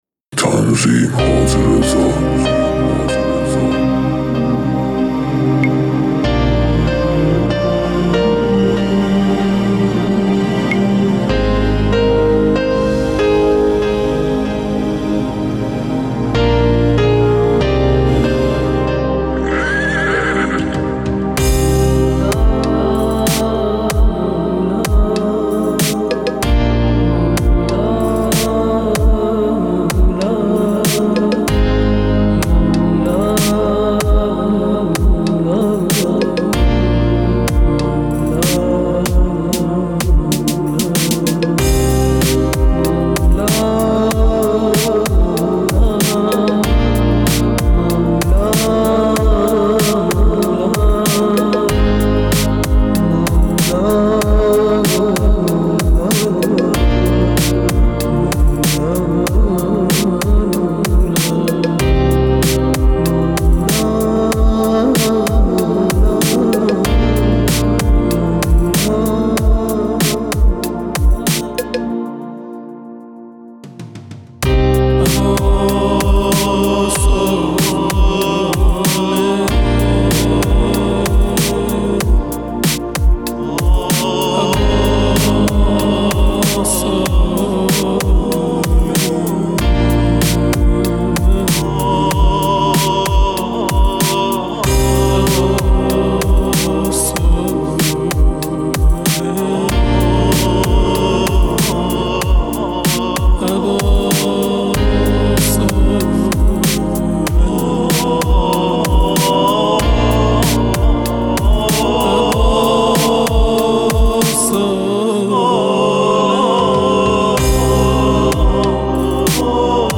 تهیه شده در(استدیو رکورد)